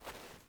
mining sounds